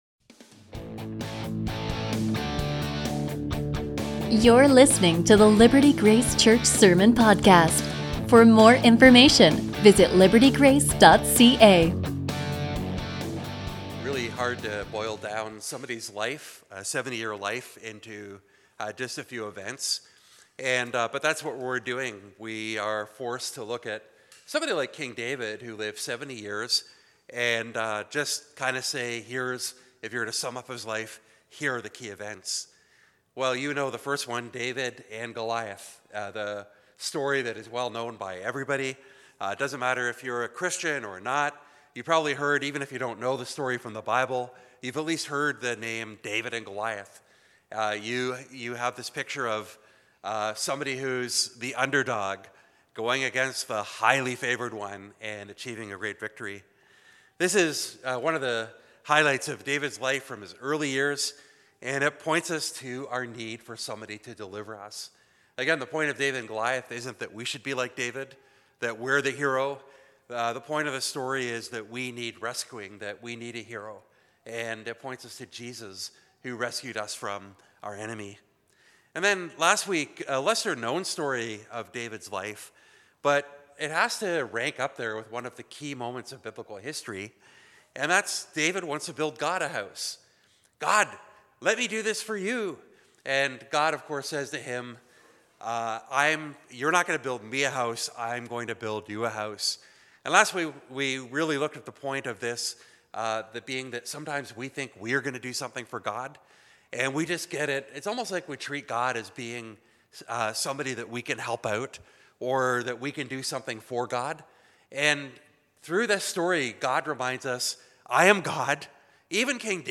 A message from the series "Year of the Bible."